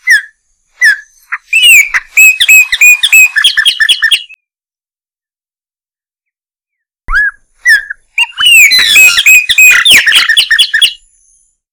Gnorimopsar chopi - Mirlo charrúa